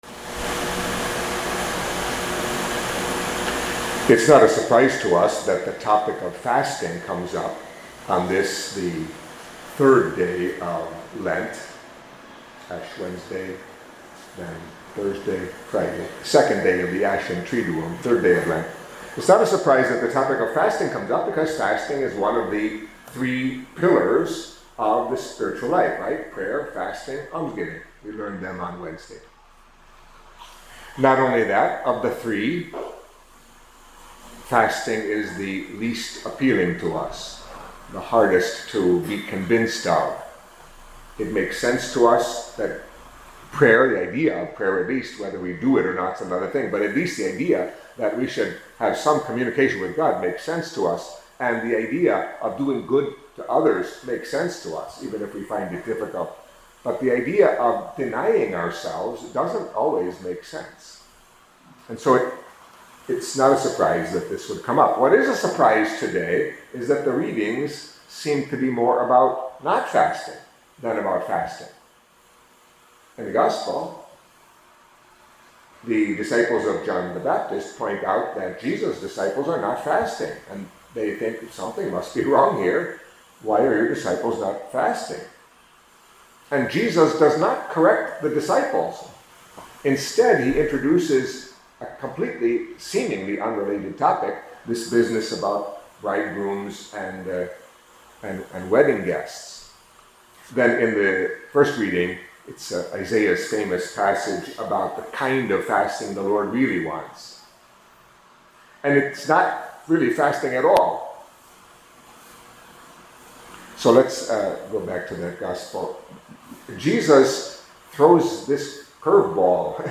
Catholic Mass homily for Friday After Ash Wednesday